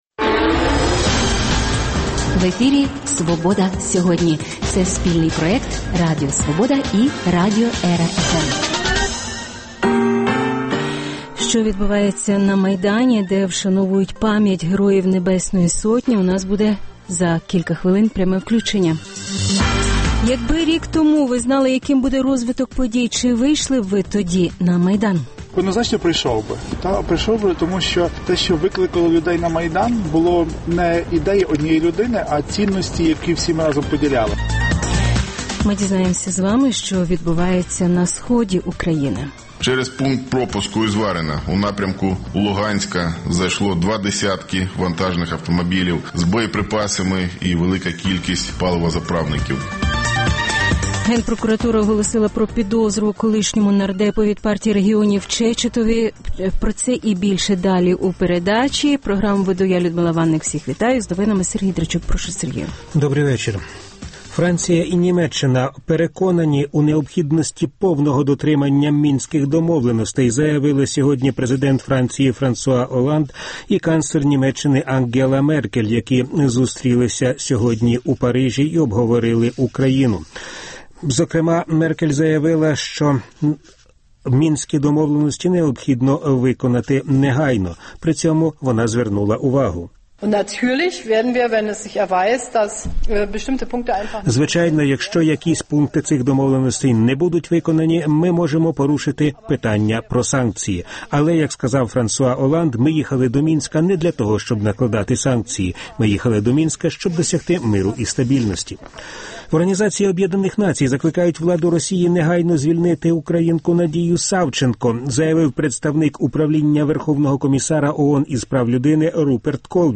Включення з Майдану з вшанування пам’яті Героїв Небесної Сотні Якби рік тому ви знали, яким буде розвиток подій, чи вийшли б тоді на Майдан? Що відбувається на Сході України?